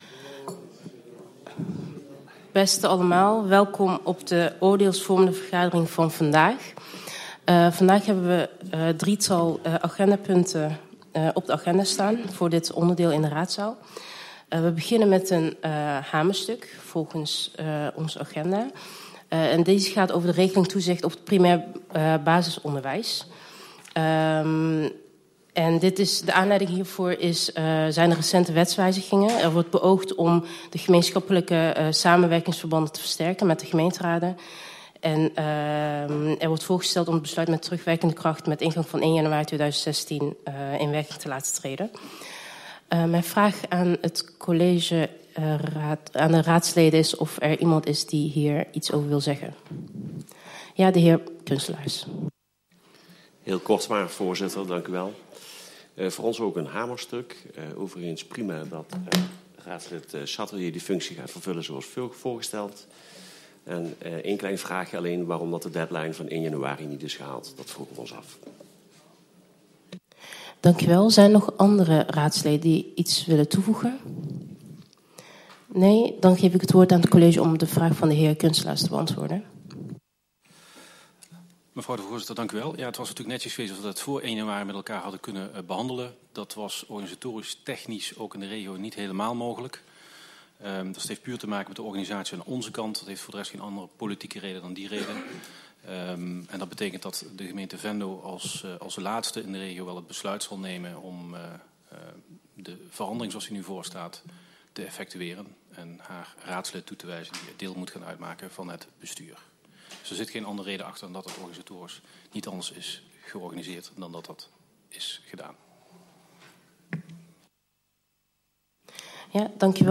Oordeelsvormende raadsvergadering 13 januari 2016 19:00:00, Gemeente Venlo